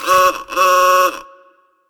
Klaxon
Mélodies maniques